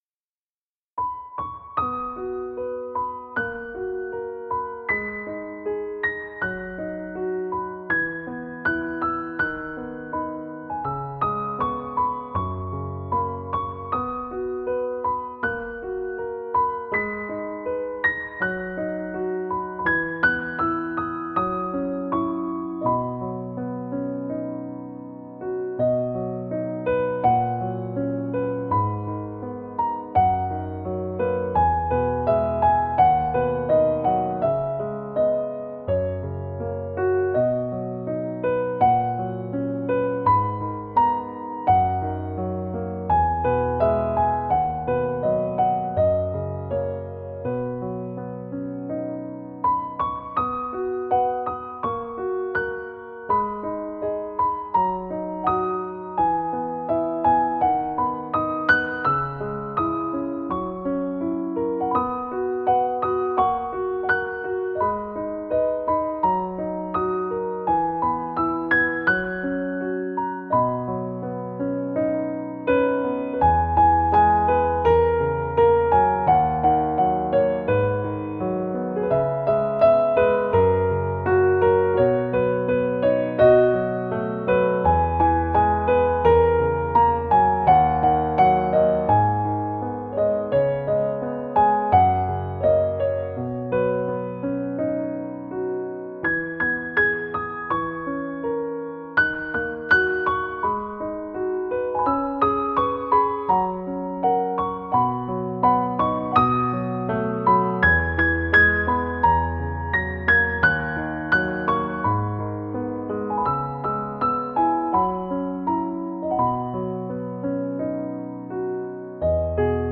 瞬间的永恒-钢琴曲.mp3